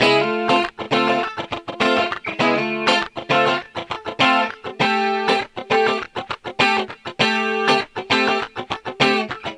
Loops guitares rythmique- 100bpm 2
Guitare rythmique 28